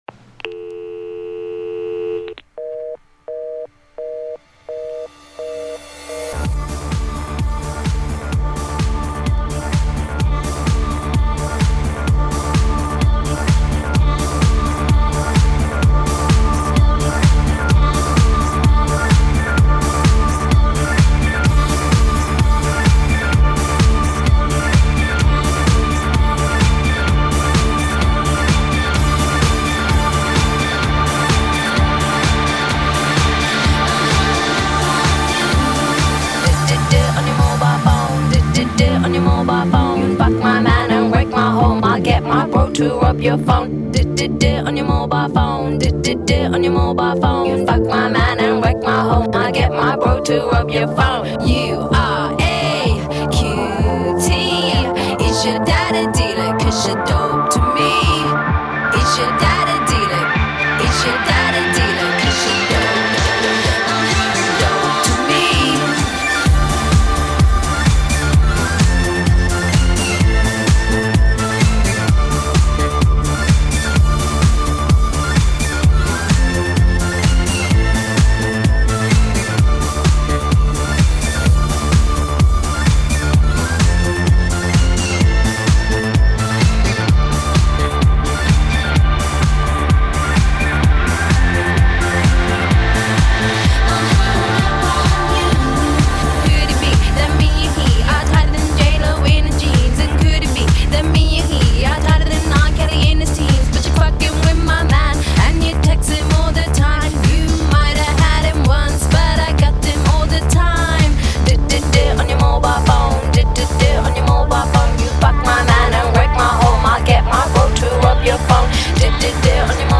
mash up